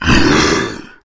troll_commander_attack.wav